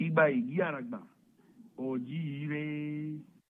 Saludo yoruba a la ceiba.